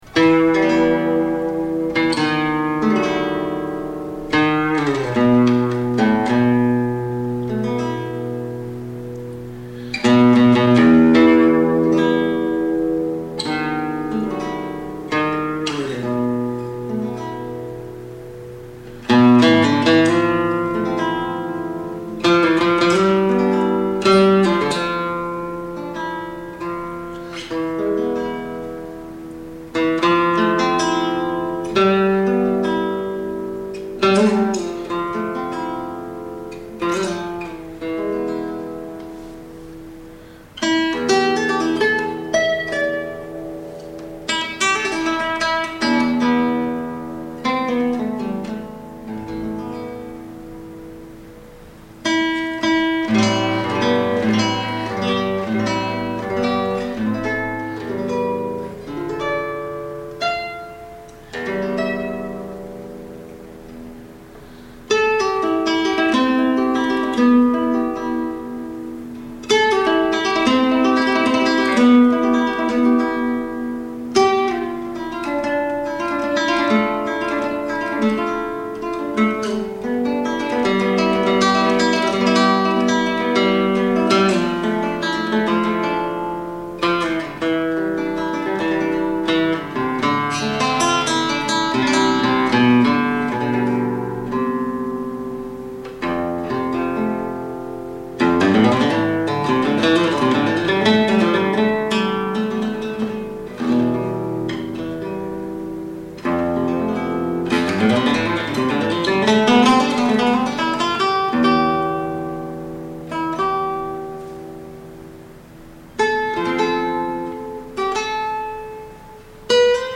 Original Compositions for Guitar